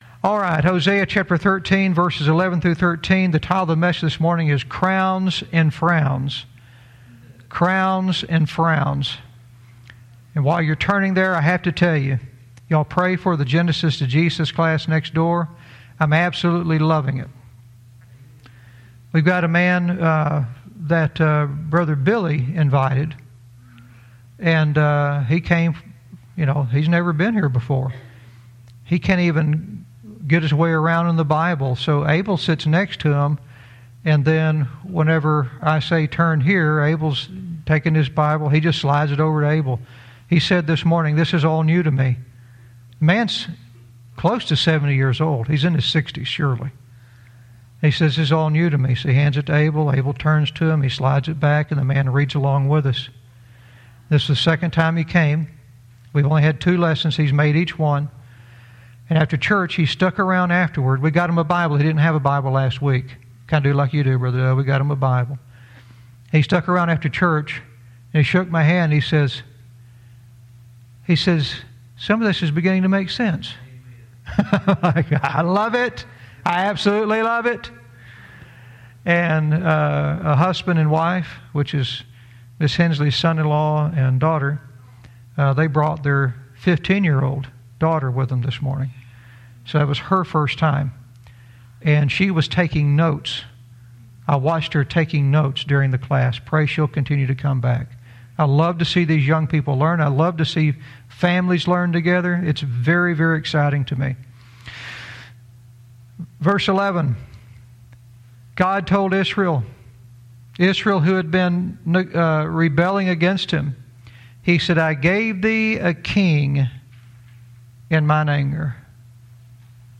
Verse by verse teaching - Hosea 13:11-13 "Crowns and Frowns"